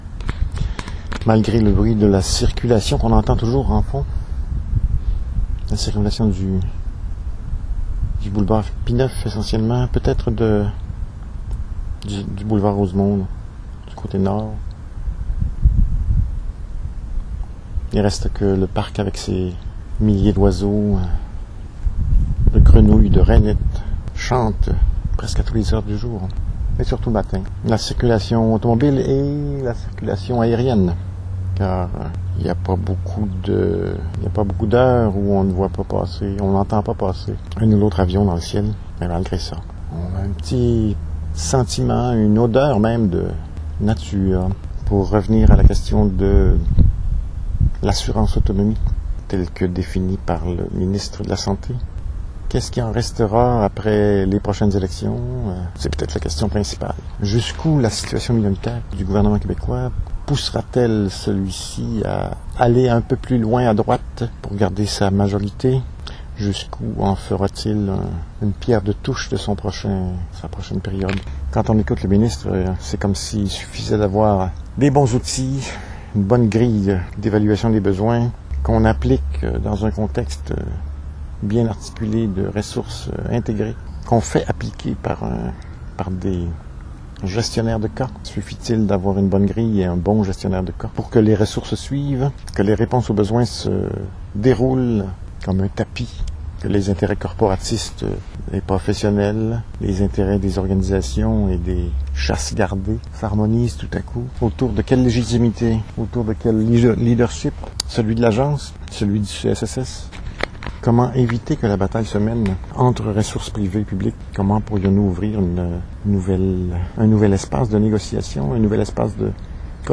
Ici j’ai poursuivi la réflexion, en marchant.
soliloque de 9,14 minutes (mp3).